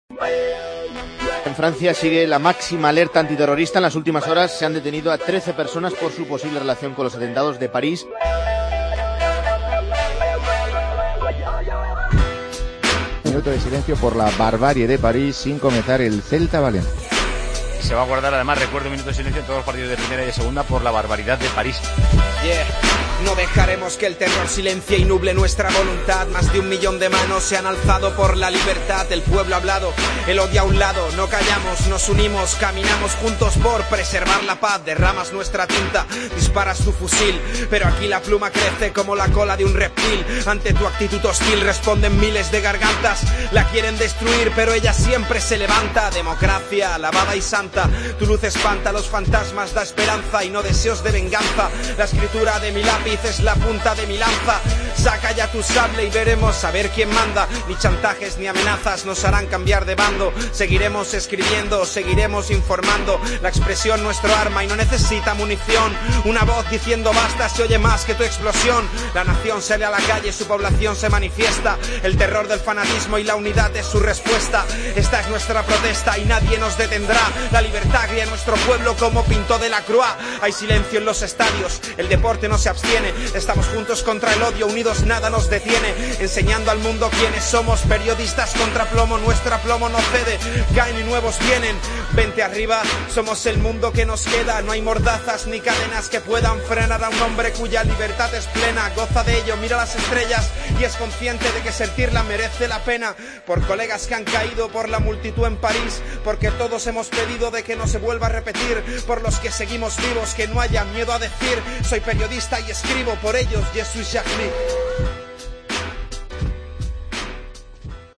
El rap
Tiempo de Juego a ritmo de rap